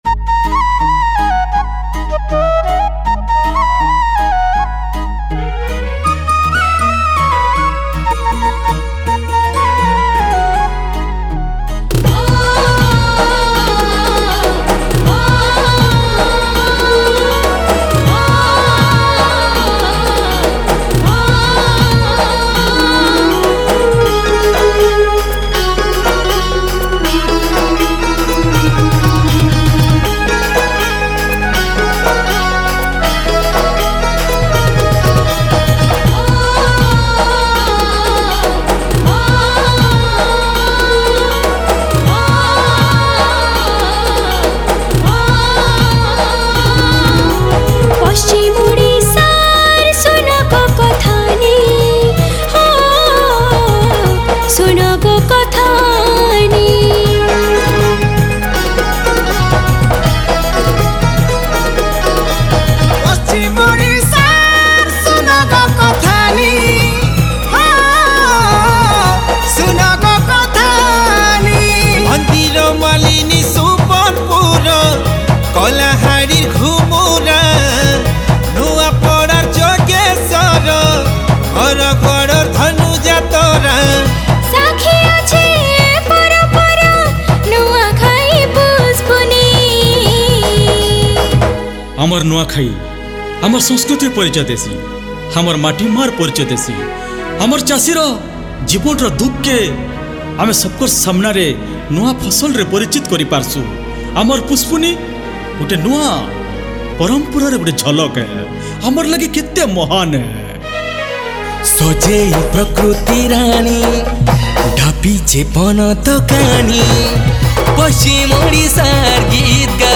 CATEGORY_STUDIO VERSION